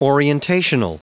Prononciation du mot : orientational